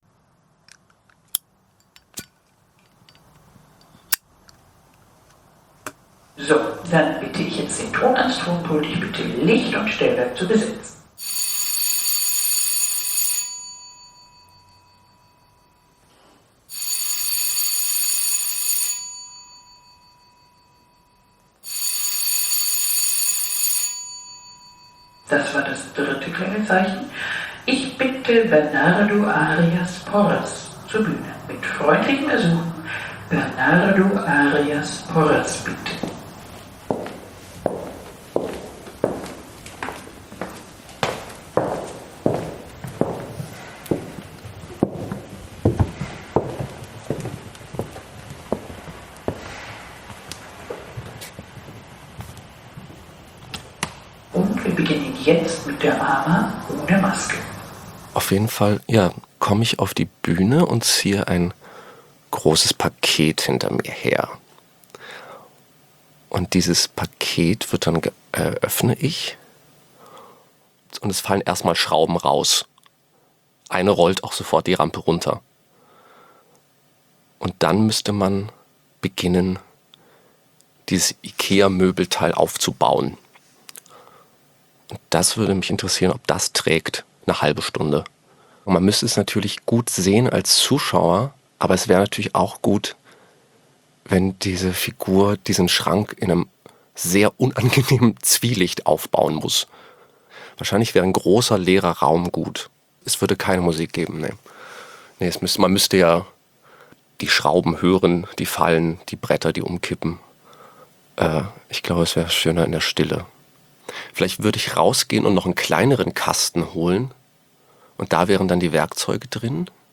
Im Podcast „AmA ohne Maske“ erzählen die Schauspieler*innen der Münchner Kammerspiele von sich: von den Menschen, die das Theater in den nächsten Jahren prägen werden.